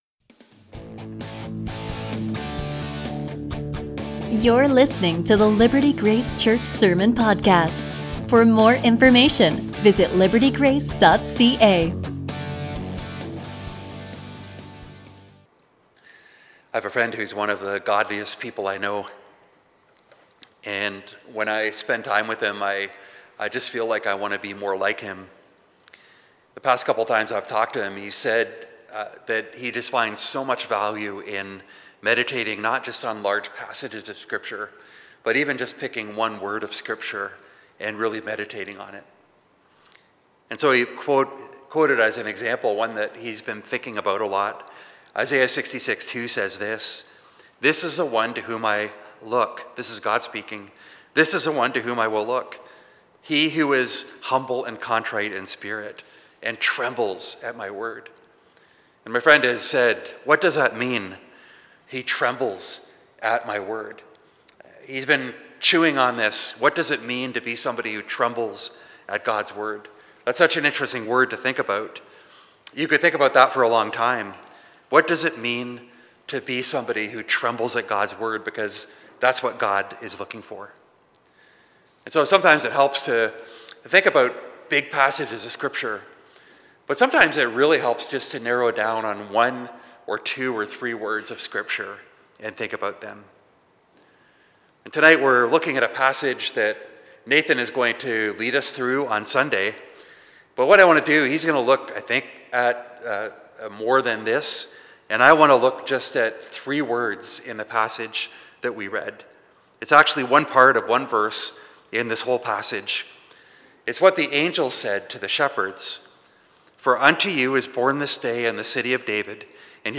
A sermon from Luke 2:11